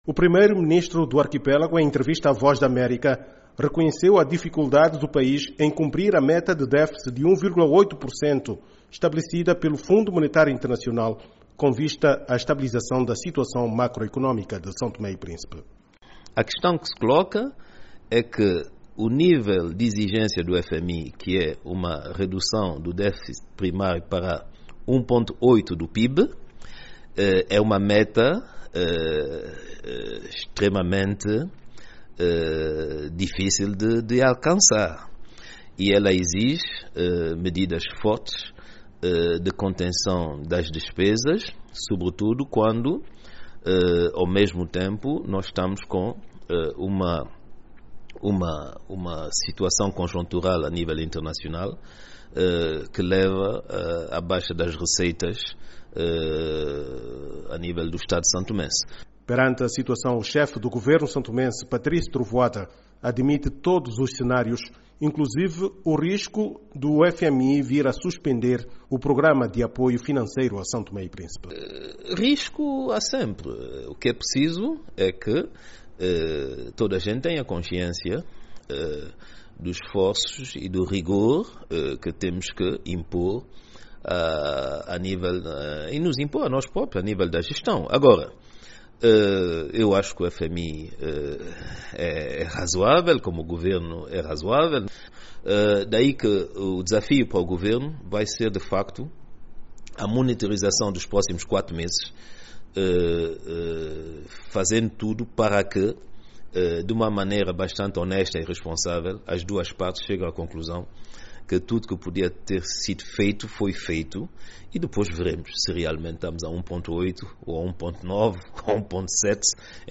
Em entrevista à VOA, Trovoada diz que o nível de exigência do FMI é muito elevado e implicará fortes medidas de contenção de despesas.